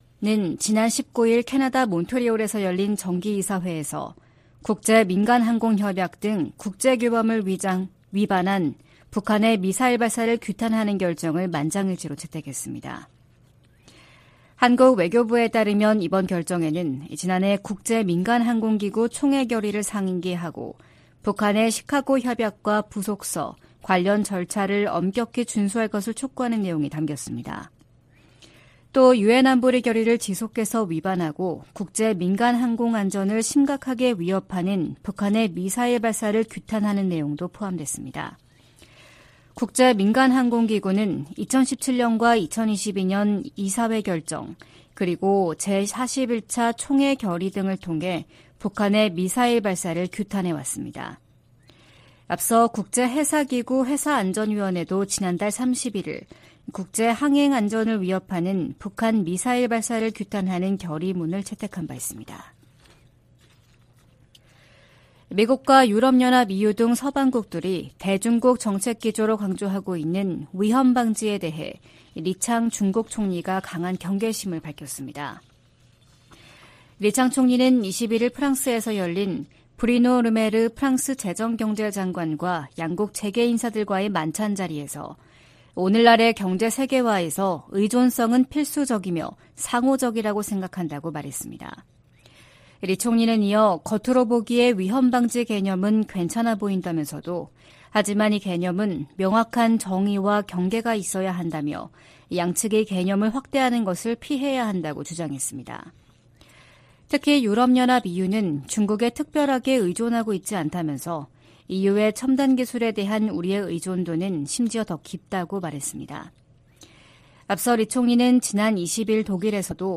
VOA 한국어 '출발 뉴스 쇼', 2023년 6월 23일 방송입니다. 미국 국무부는 중국이 북한 문제를 해결할 역량과 책임이 있다는 점을 거듭 강조했습니다. 미국은 자산 동결 등의 조치를 통해 북한이 탈취 암호화폐를 미사일 프로그램에 사용하는 것을 막았다고 법무부 고위 관리가 밝혔습니다. 주한미군 고고도 미사일 방어체계 즉 사드(THAAD) 기지가 인체에 미치는 영향은 미미하다고 한국 정부의 환경영향 평가가 결론 지었습니다.